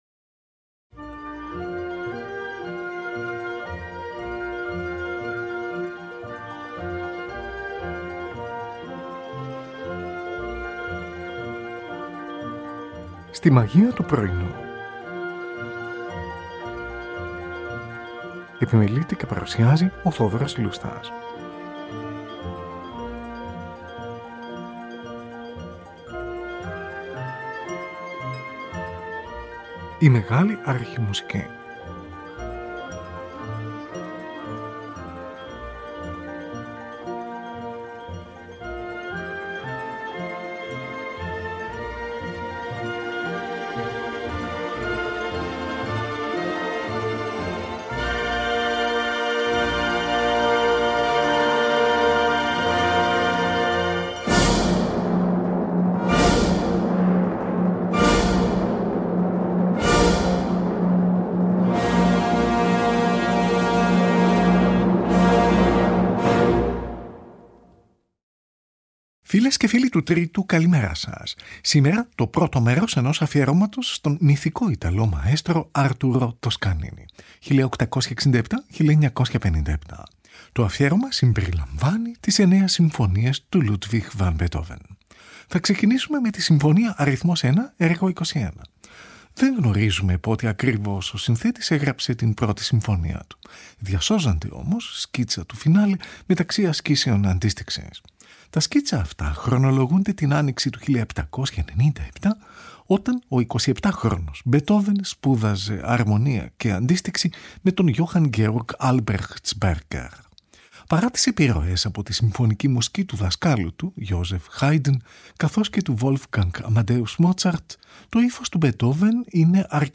Τη Συμφωνική του NBC διευθύνει ο Arturo Toscanini. Zωντανή ραδιοφωνική μετάδοση , στις 28 Οκτωβρίου 1939.
Zωντανή ραδιοφωνική μετάδοση , στις 11 Νοεμβρίου 1939.